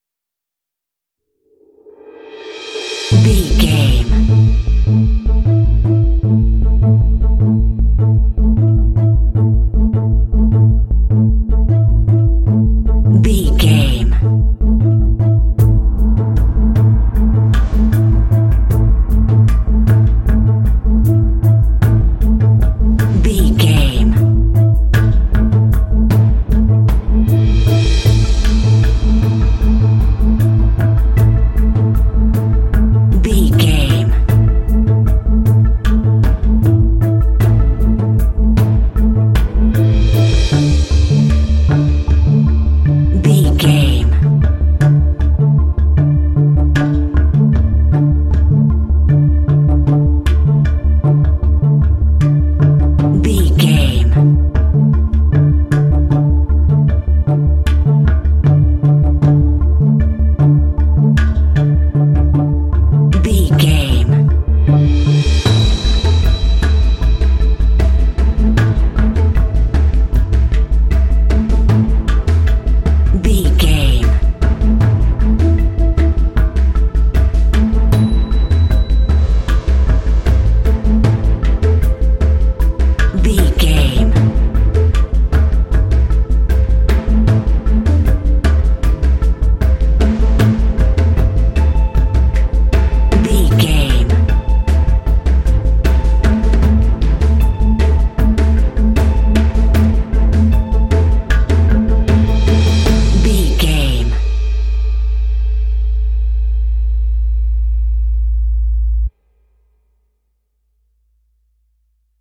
Aeolian/Minor
tension
suspense
dramatic
contemplative
drums
strings
synthesiser
cinematic
film score